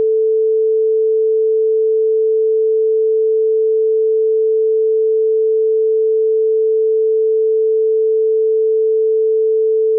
front_sin440_FuMa.wav